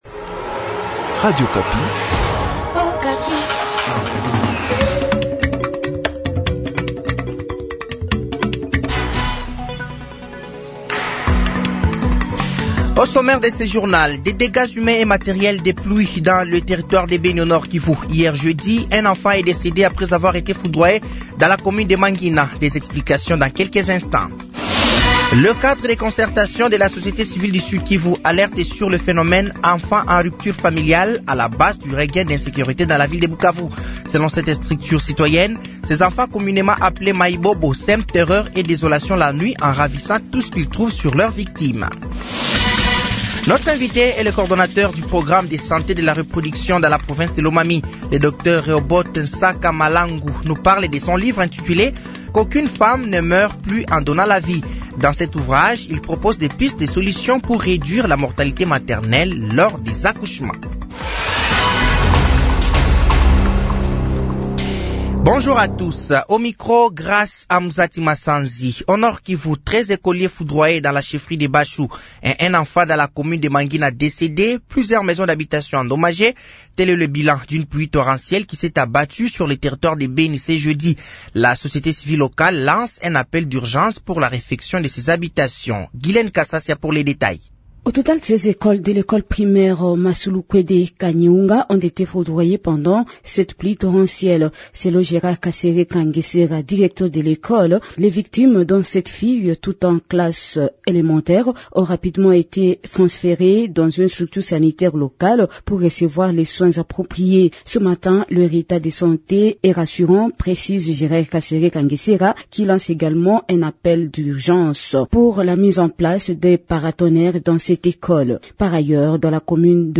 Journal français de 15h de ce vendredi 20 décembre 2024